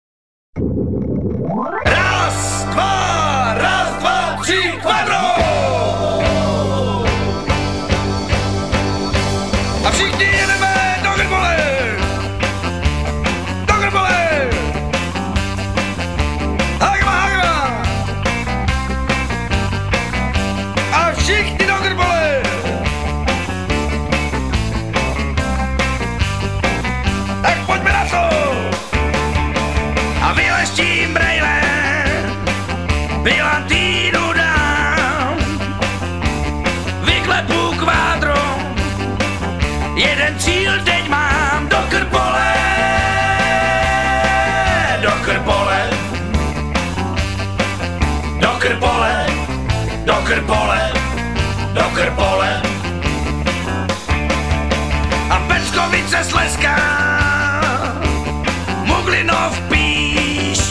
Rock’n’Roll!